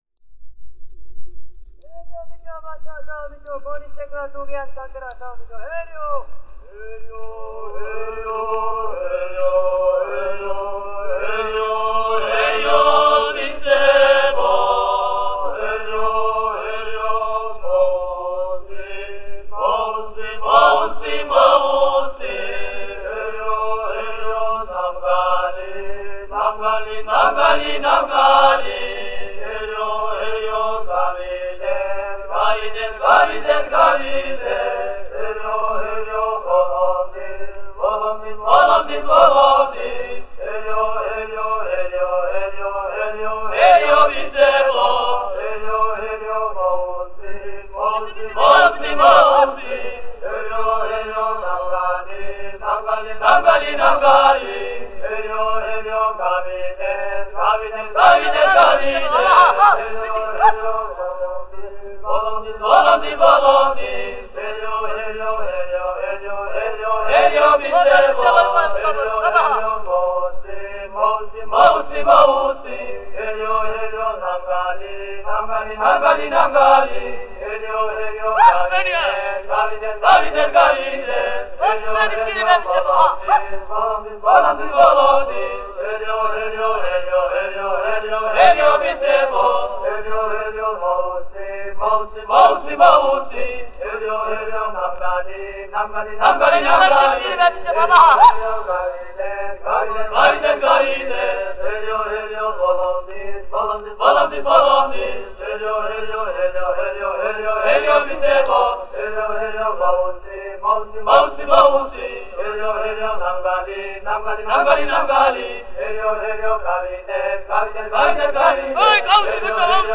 ensemble of singers (all recorded in 1914
A work song sung during scything